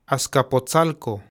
Azcapotzalco (Classical Nahuatl: Āzcapōtzalco [aːskapoːˈt͡saɬko]
Askapotsalko.ogg.mp3